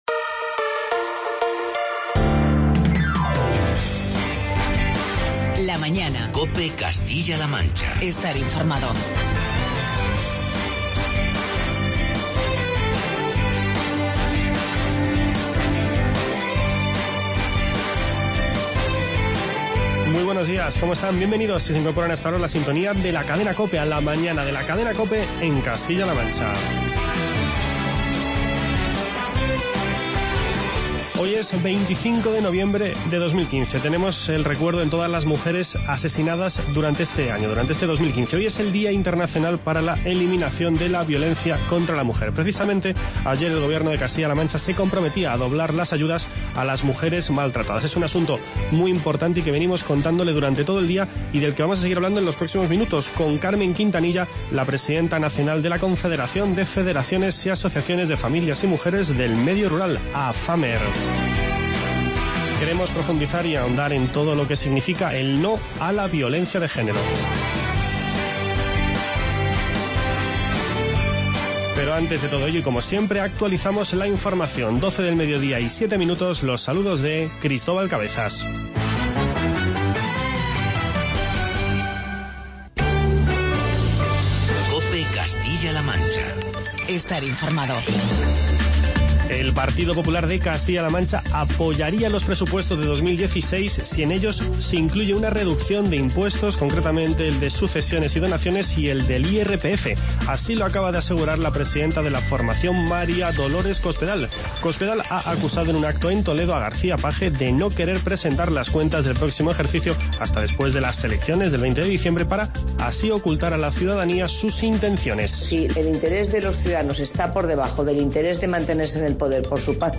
Charlamos con Carmen Quintanilla, presidenta nacional de la Confederación de Federaciones y Asociaciones de...